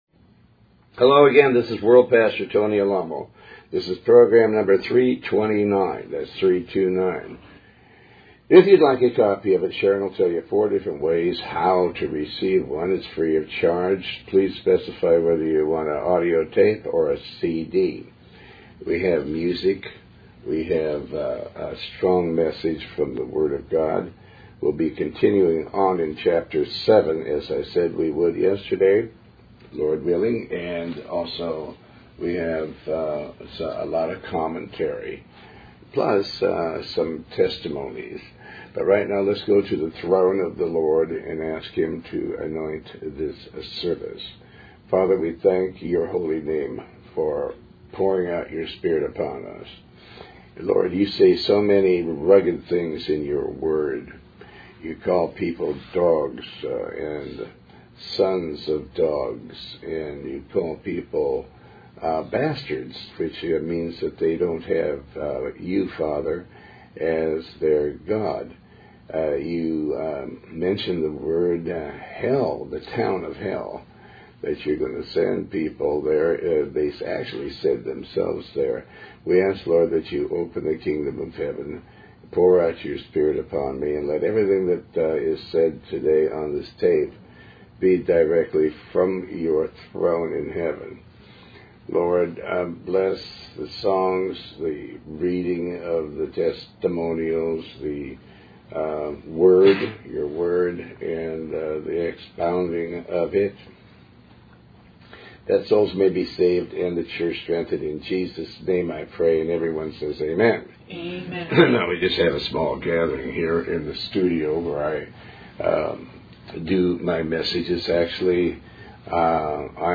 Tony Alamo Talk Show
Show Host Pastor Tony Alamo